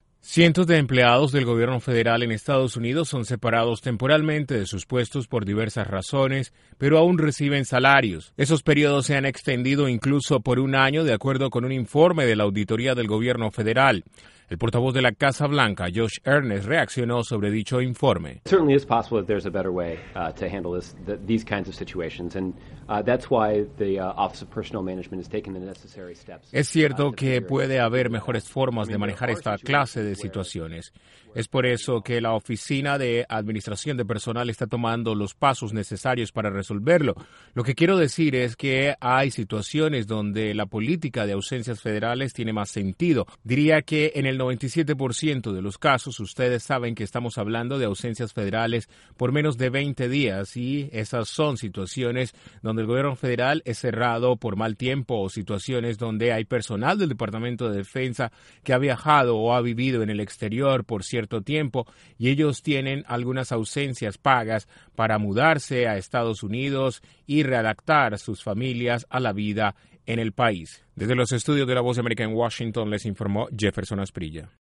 La Casa Blanca reaccionó al informe de auditoría que llama la atención sobre excesos en ausencias pagas de trabajadores del gobierno federal en Estados Unidos. Desde la Voz de América en Washington informa